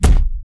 body_hit_large_32.wav